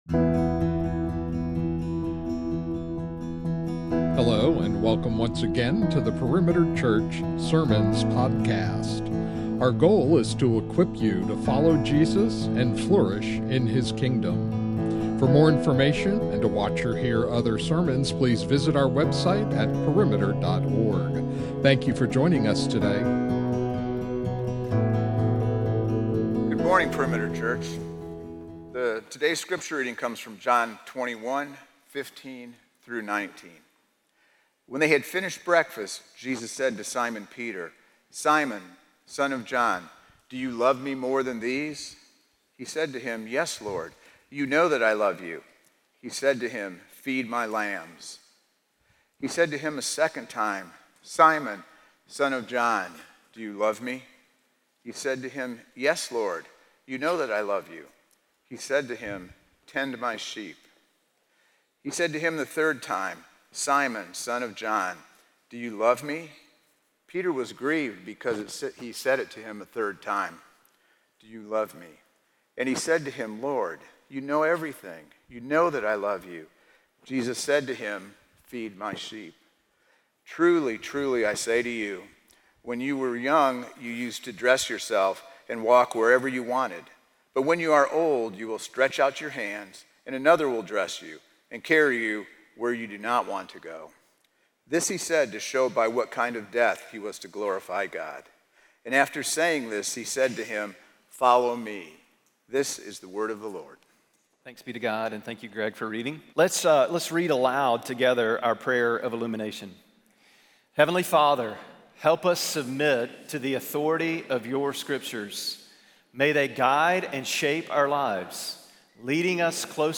The official weekly sermon and teaching podcast of Perimeter Church in Johns Creek, GA.